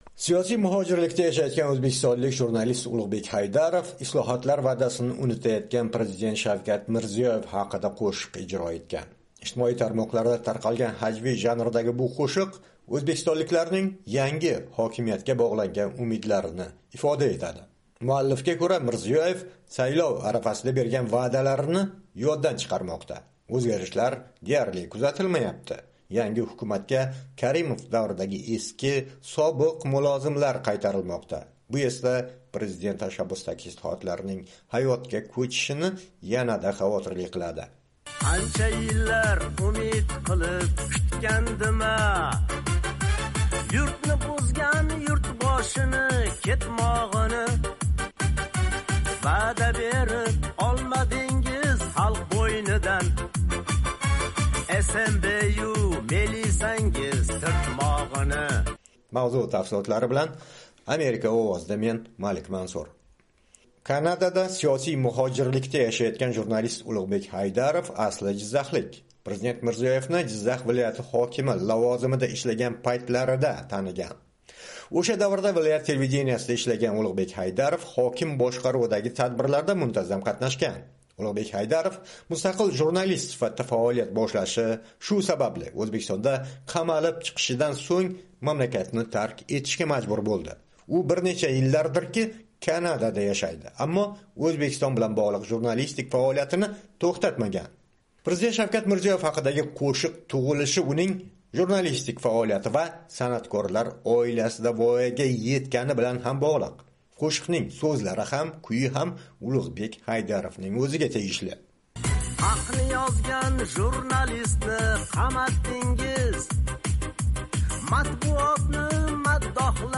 Mirziyoyev haqida satirik qo'shiq